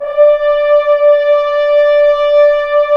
Index of /90_sSampleCDs/Roland LCDP06 Brass Sections/BRS_F.Horns 1/BRS_FHns Ambient
BRS F.HRNS0R.wav